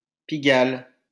Pigalle (French pronunciation: [piɡal]